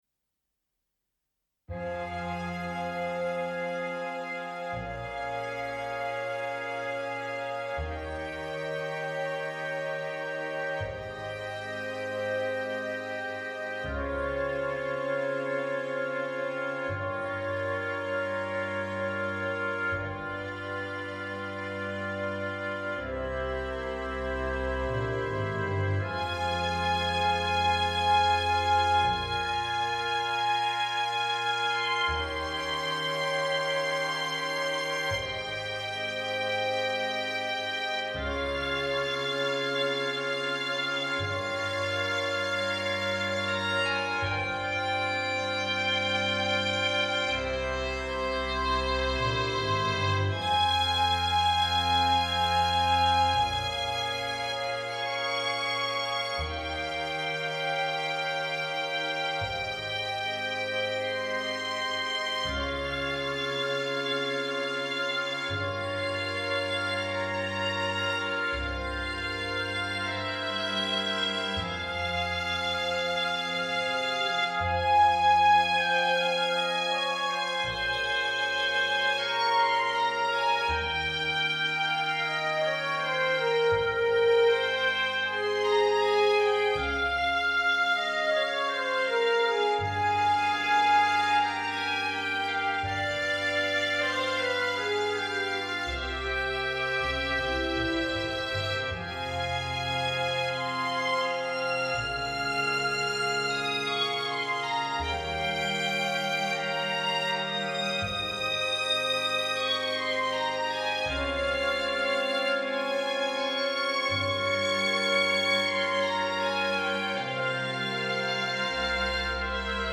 Chamber Orchestra / Small Ensemble
Instrumentation: - flexible/ Flute, Oboe, Clarinet Bb,
Bassoon, Solo / Unison Soprano or other solo
instrument, Strings
Difficulty Level: Easy - Moderate (Key F minor)
A beautiful arrangement for Chamber Orchestra or